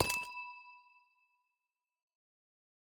Minecraft Version Minecraft Version latest Latest Release | Latest Snapshot latest / assets / minecraft / sounds / block / amethyst / step13.ogg Compare With Compare With Latest Release | Latest Snapshot
step13.ogg